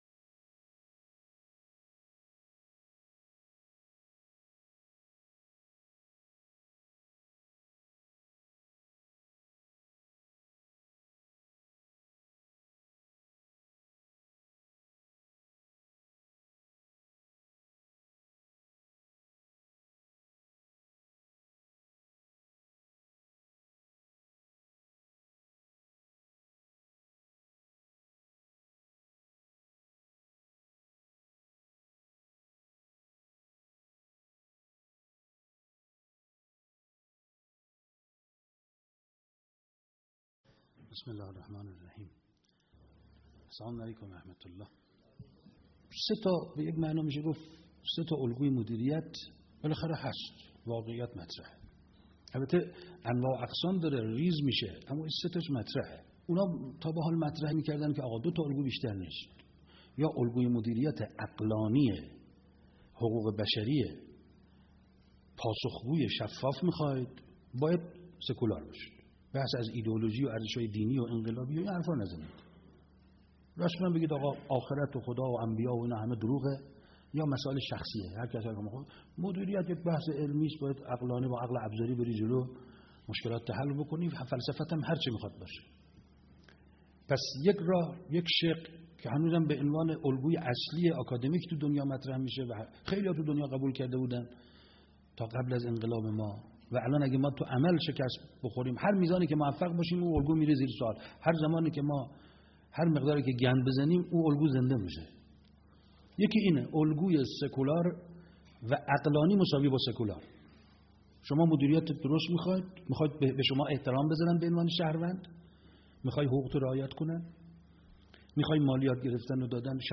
بزرگداشت هفته دولت- در جمع مدیران خراسان رضوی- 90 {شهرداری مشهد}